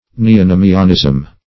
\Ne`o*no"mi*an*ism\
neonomianism.mp3